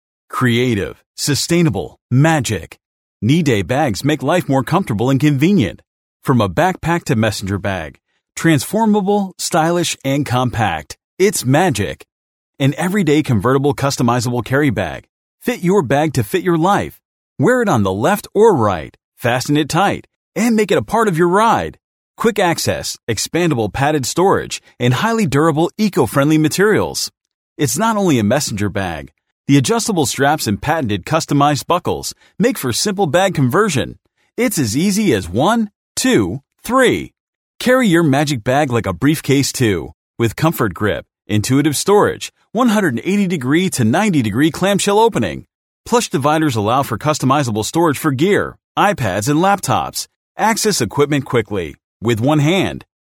男6 背包产品广告
男6 背包产品广告.mp3